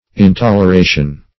intoleration.mp3